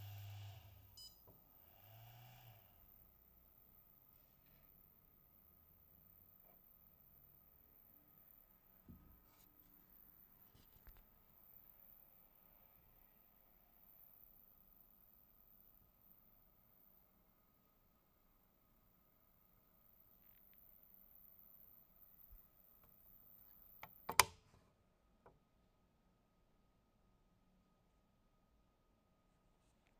High frequency acoustic noise issue
When the steppermotors are initialized and running the power supply give off a very loud high frequency acoustic noise, 15 kHz to 22+ kHz.
I cant hear anything from your audiofile, would have been interesting to see an FFT from your audiofile aswell. My guess is that switching noise from driving the stepper motors causes some capacitors or inductors to vibrate.